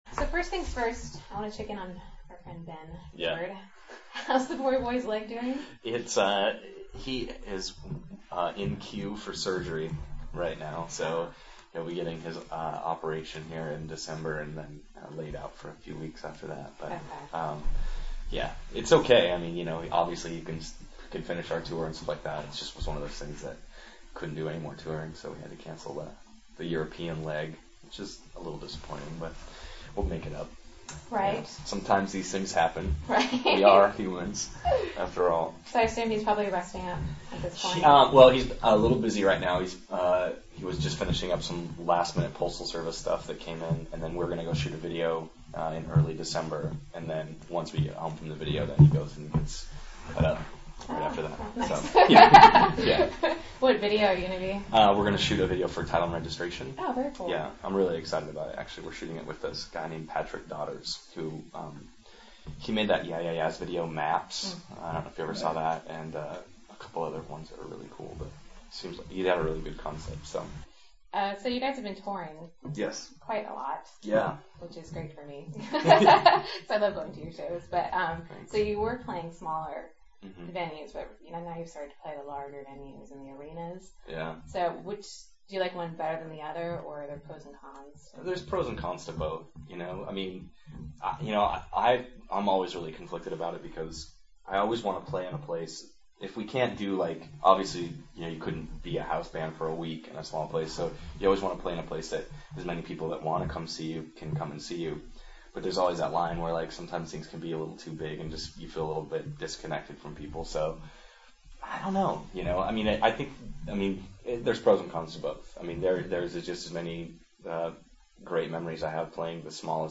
Death Cab for Cutie: They Eat Brains MP3 Interview Link Jay-Z and R. Kelly in the squared circle, only one man survives